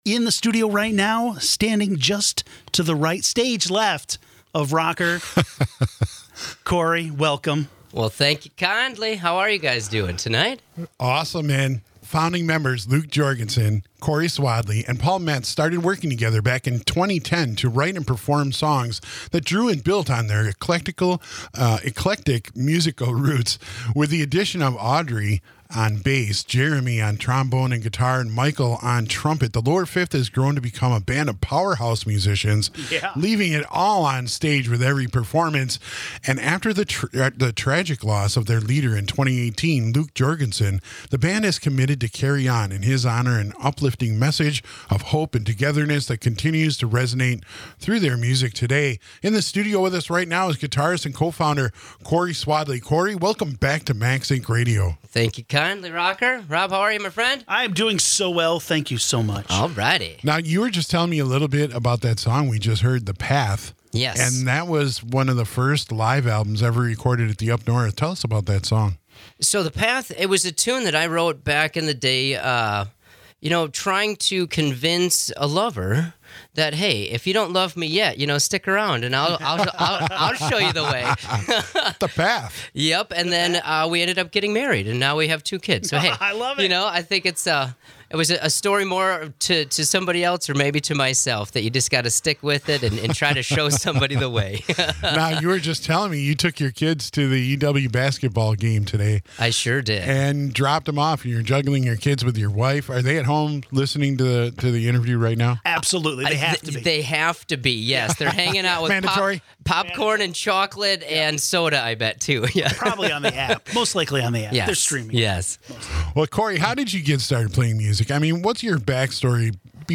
performing live
Bass